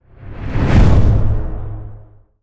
cinematic_deep_low_whoosh_impact_03.wav